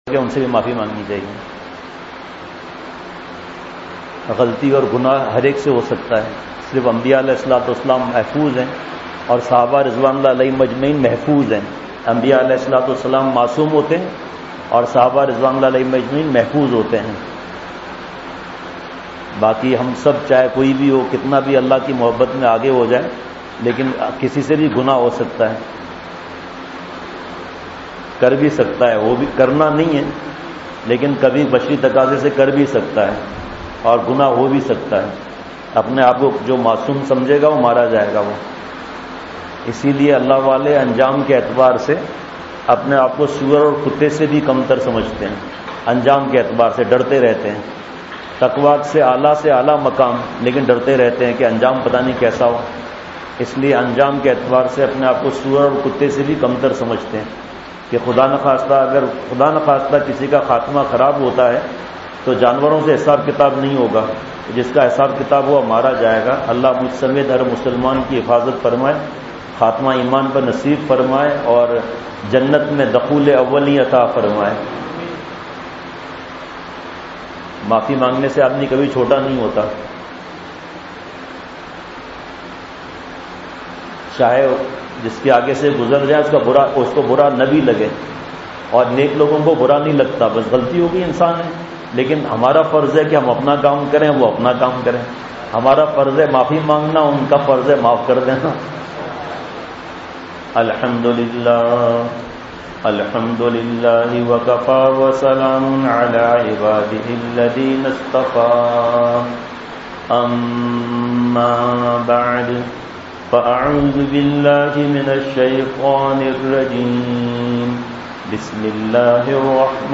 ( مسجدِ اخترمیں خاص بیان )
Mehboob e Haqeqi Srif ALLAH Ki Zaat Hay Masjid e Ahktar.mp3